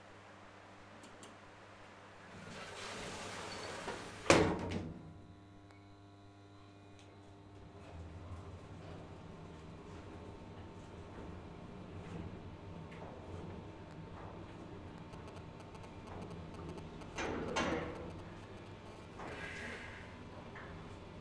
Italian ambience
17_Inside Lift_doors closing_ascentig_doors opening_no voices.wav